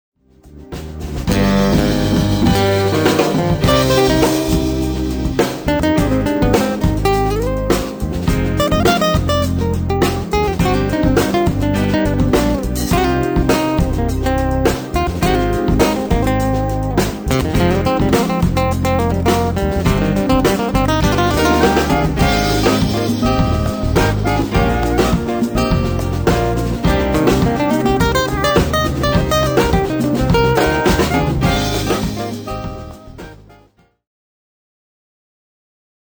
and contemporary jazz.
His nylon string guitar sings over original
hypnotic grooves, complimenting many venues